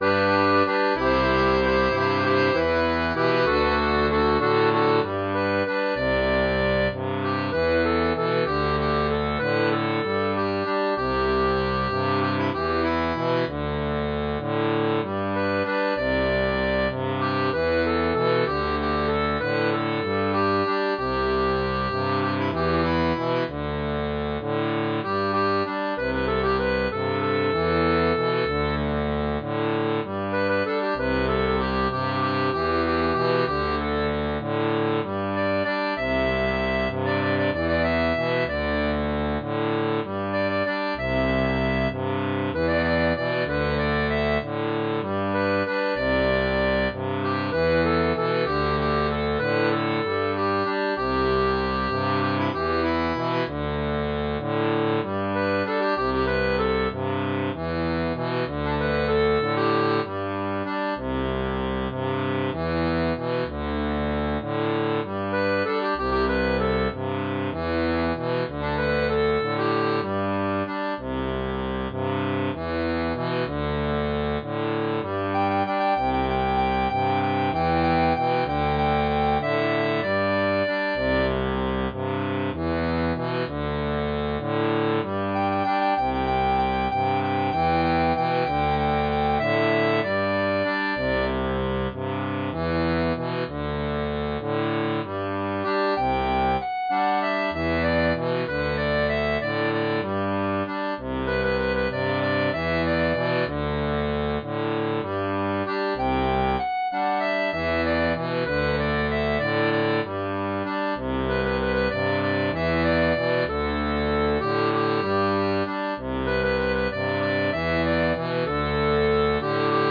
• Une tablature pour diato à 3 rangs
Type d'accordéon
Pop-Rock